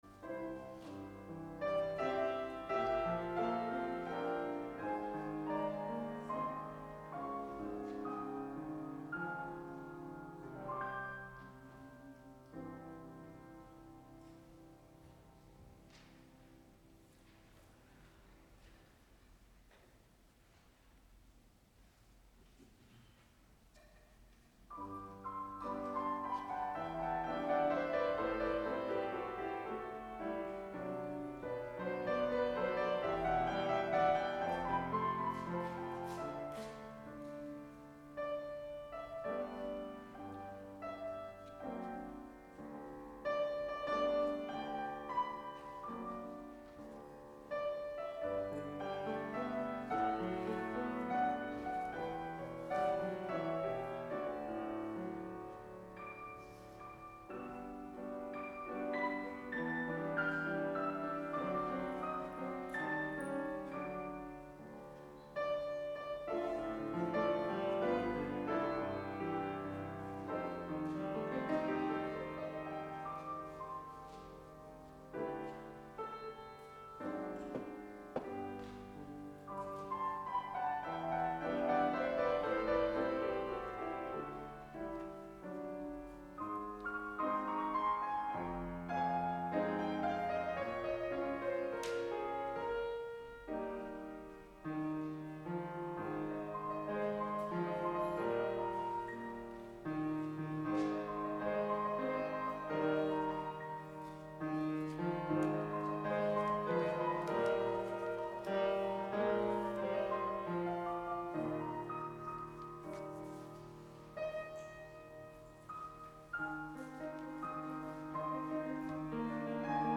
Please click below for audio recording of this worship service.